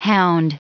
Prononciation du mot hound en anglais (fichier audio)
Prononciation du mot : hound